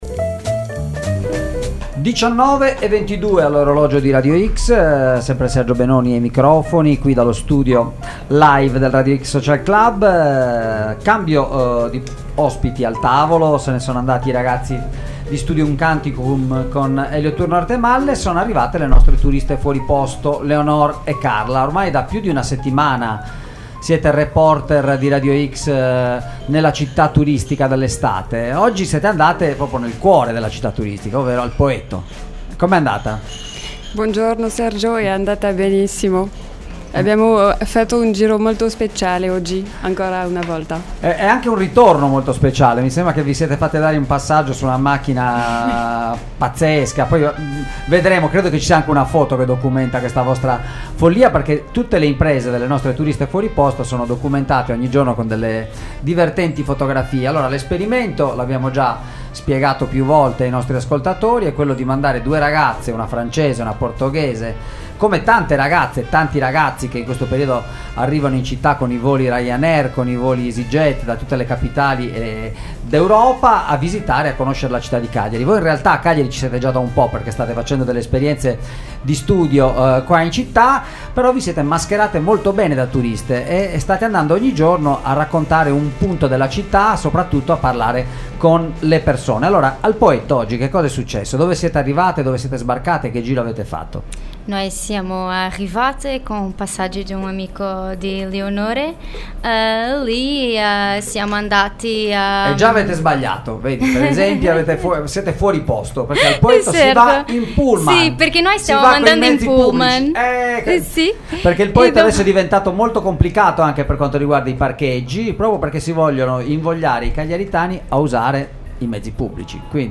Le nostre “Turiste fuori posto” oggi si sono dedicate alla tintarella e hanno fatto visita al Poetto, per scoprire cosa ne pensano i cagliaritani della loro spiaggia e per ascoltare la voce di chi vive e lavora in riva al mare!